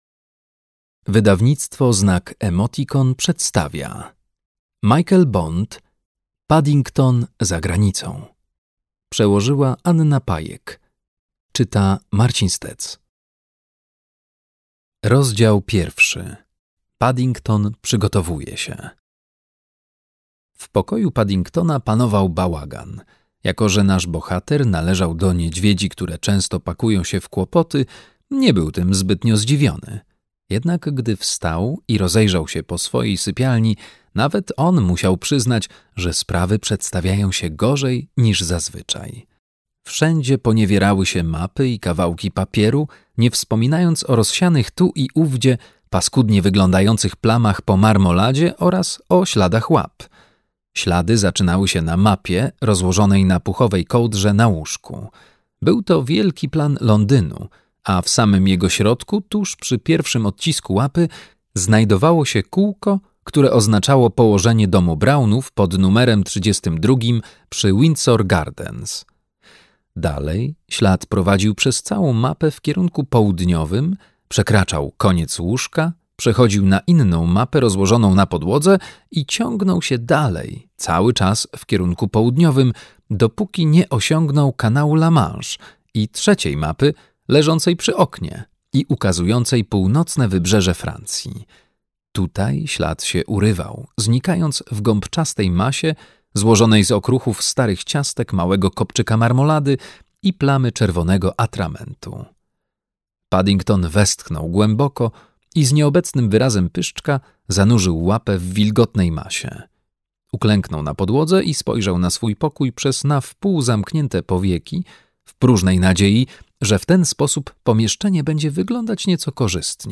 Paddington za granicą - Michael Bond - audiobook